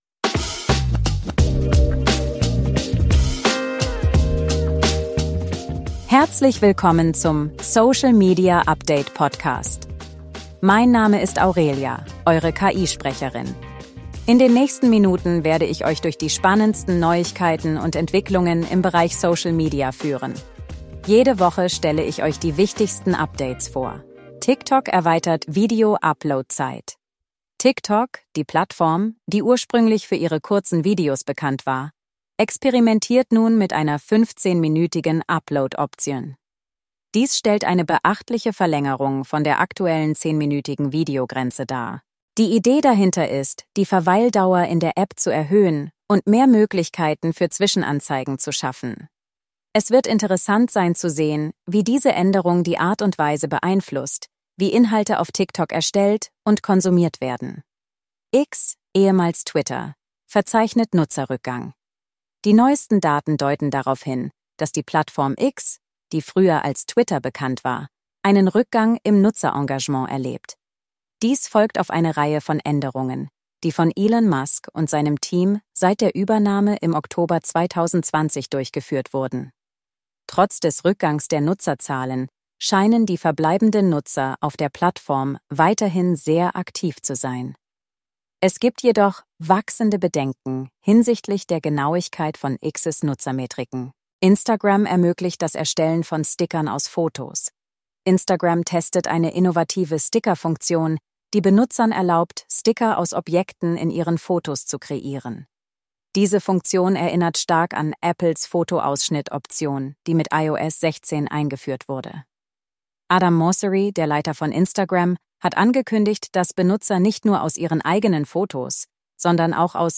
Unsere KI Host Aurelia fasst für euch die wichtigsten Meldungen der Woche zusammen